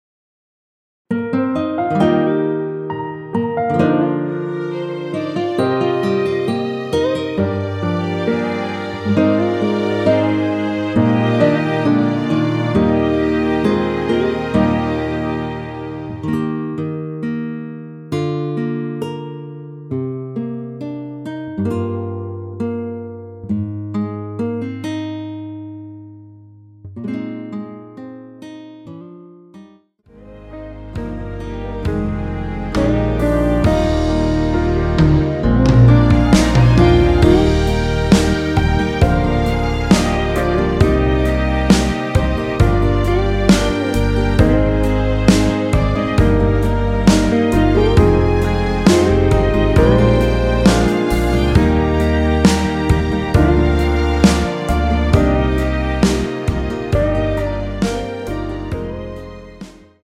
원키에서(-2)내린 MR입니다.
Eb
앞부분30초, 뒷부분30초씩 편집해서 올려 드리고 있습니다.
중간에 음이 끈어지고 다시 나오는 이유는